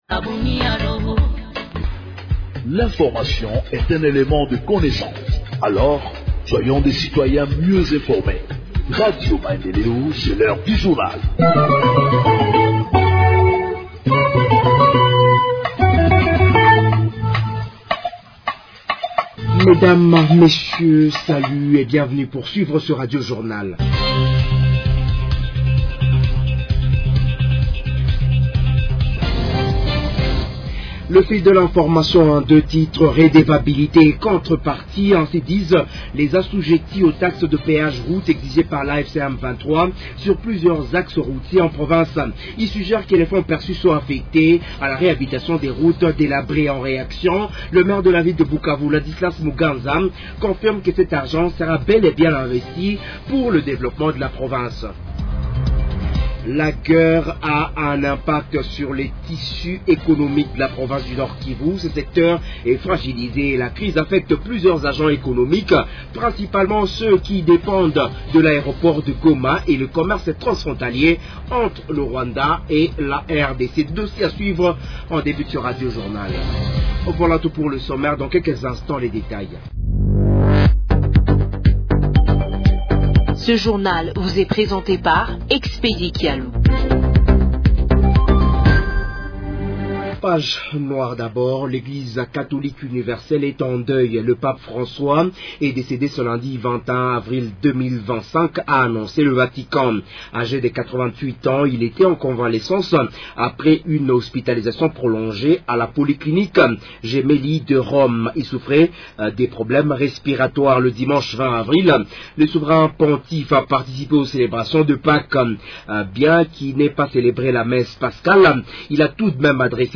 Journal en Français du 22 avril 2025 – Radio Maendeleo